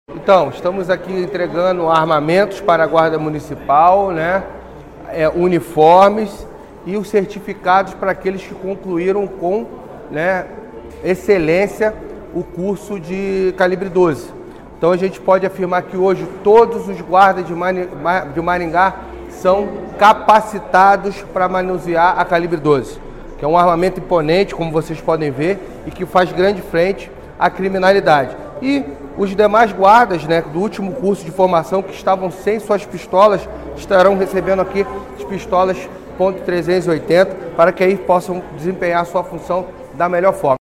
O secretário de Segurança Luiz Alves diz que todos os guardas municipais da cidade estão capacitados para utilizar armas calibre .12. Ouça: